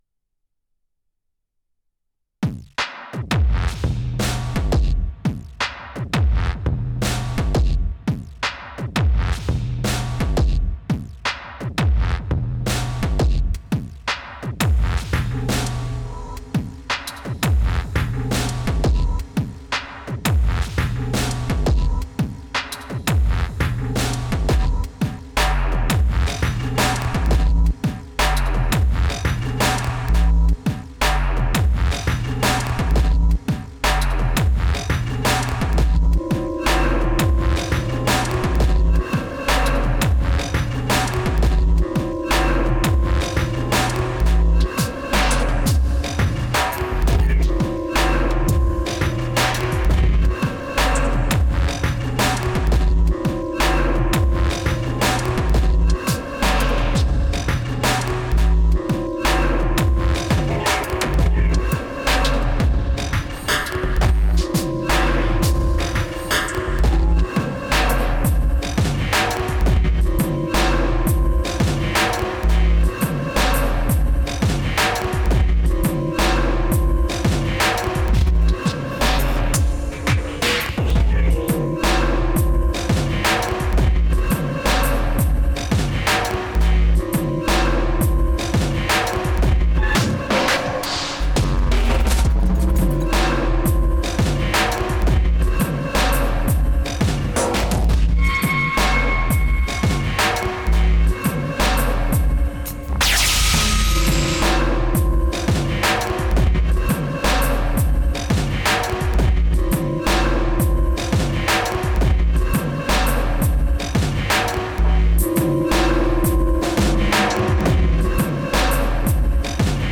Just running through a 1 bar loop here, changing up samples on the fly with some randomisation and using the perform mode.